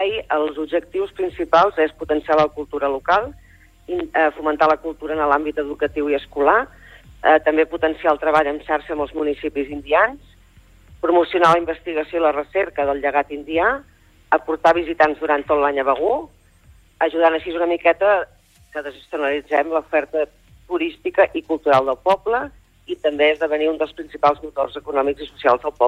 Entrevistes SupermatíSupermatí
Al Supermatí d’avui hem parlat amb l’alcaldessa de Begur Maite Selva, sobre la nova iniciativa que l’ajuntament del poble està a punt d’inaugurar: el centre d’interpretació d’indians de Catalunya.